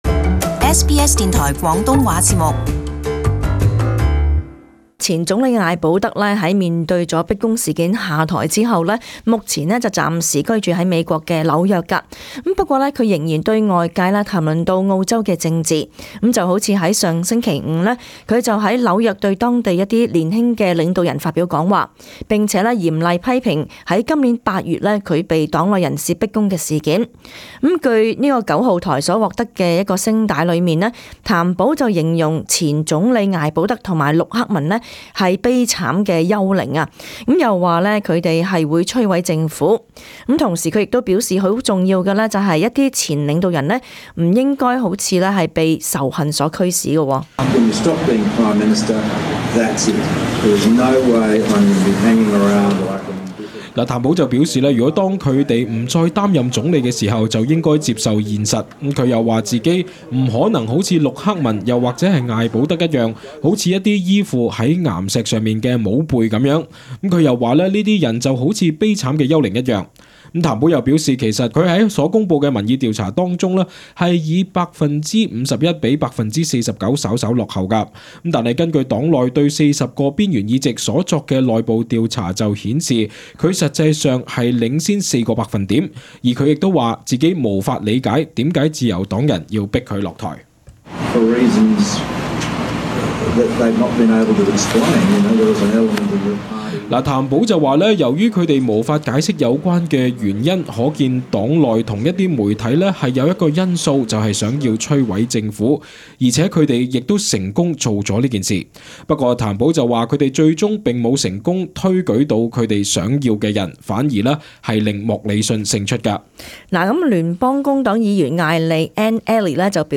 【時事報導】譚保：艾保德陸克文是「悲慘的幽靈」